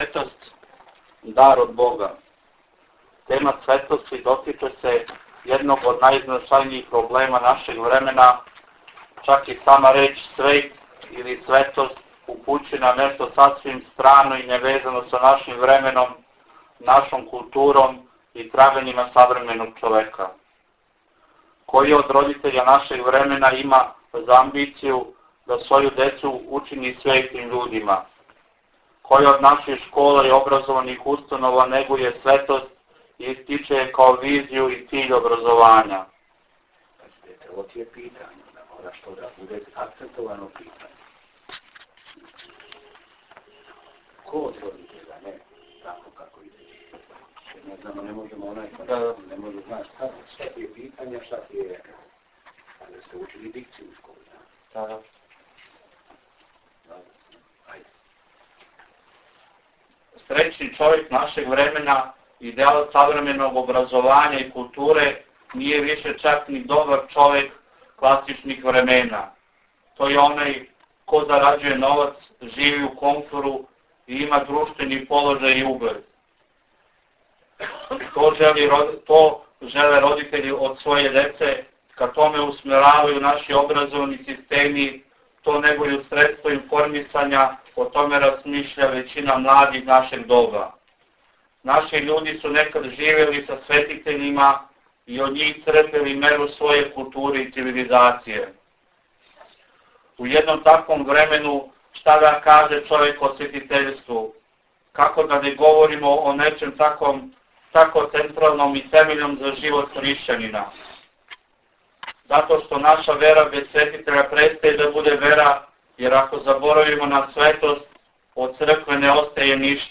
Исповест свештеника Архијерејског намесништва опленачког одржана је 02. априла 2008. године у манастиру Никоље рудничко.
Дискусија на реферат
OplenackoReferat.wav